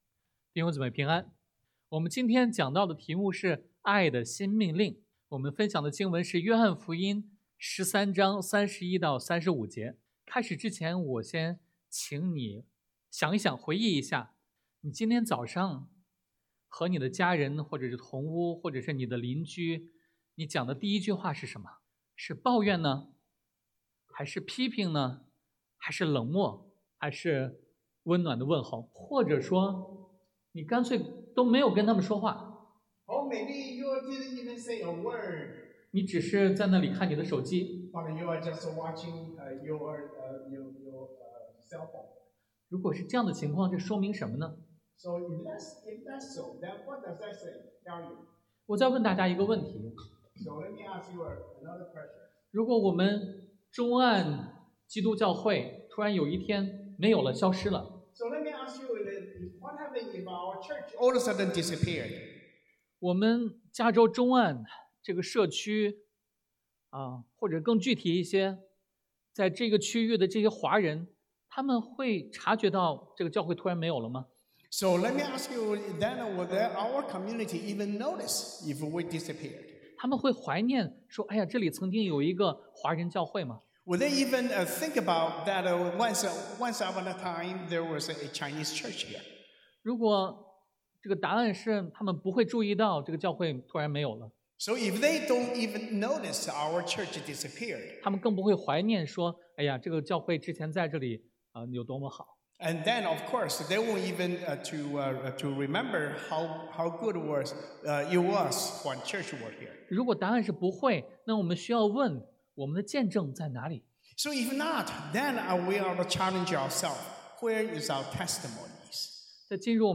Passage: 约翰福音 John 13:31-35 Service Type: Sunday AM